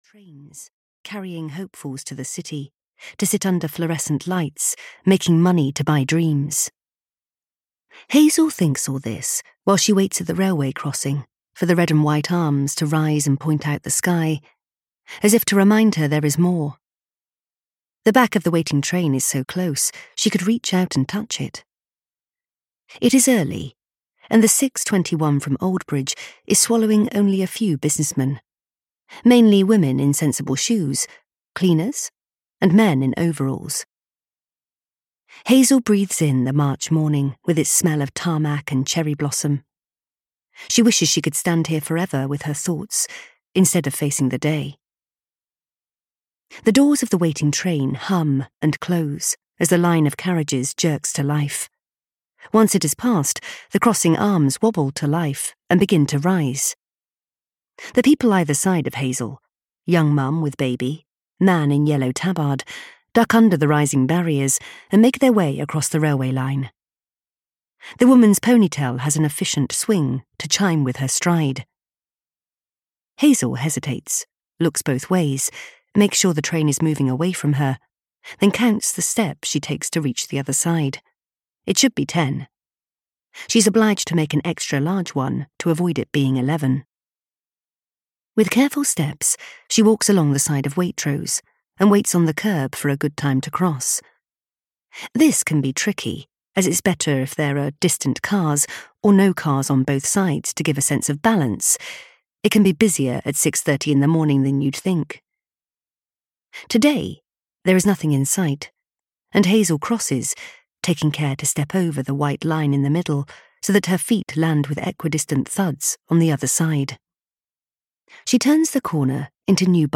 Braver (EN) audiokniha
Ukázka z knihy